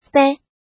怎么读
bei
bei5.mp3